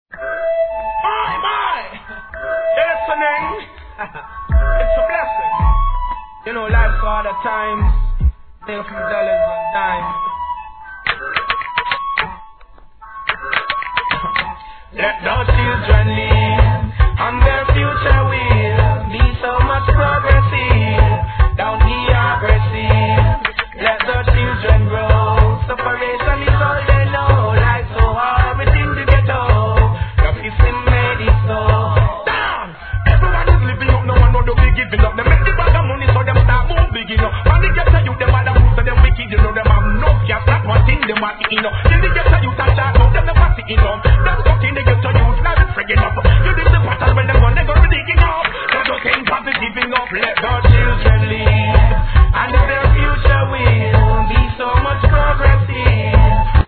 REGGAE
2003年のエスニックな好JUGGLIN'!!